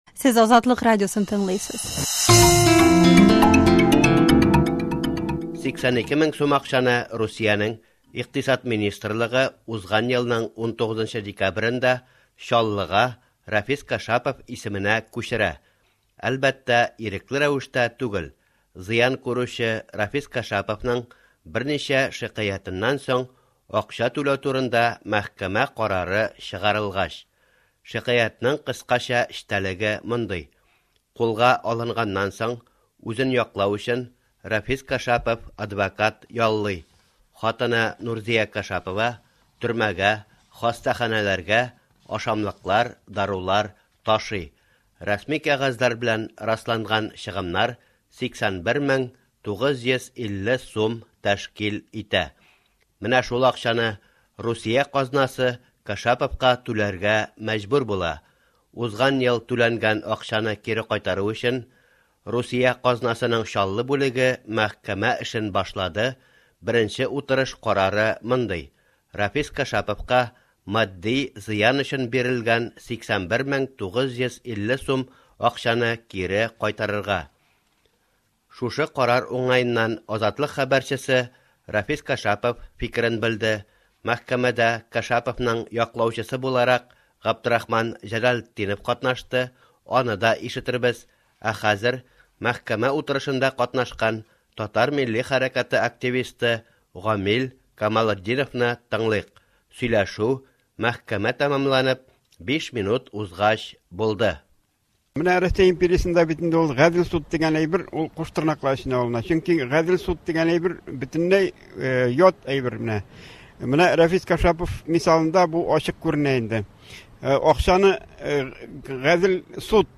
äñgämä